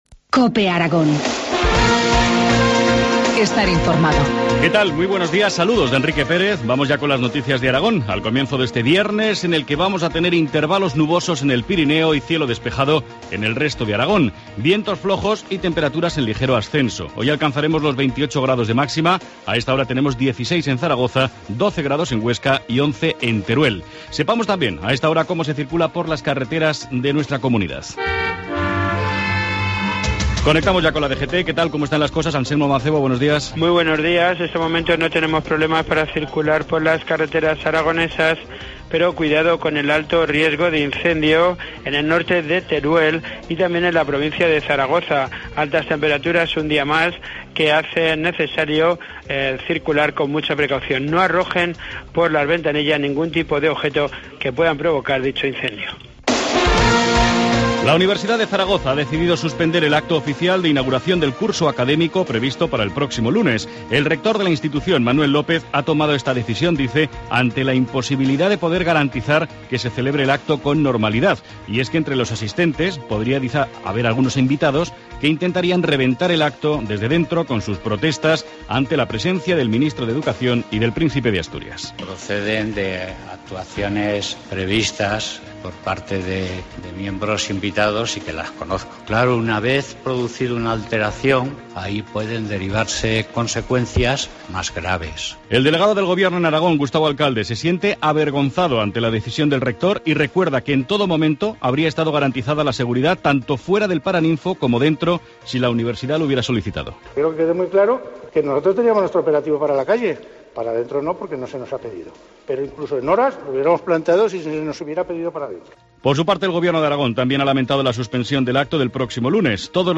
Informativo matinal, viernes 20 de septiembre, 7.25 horas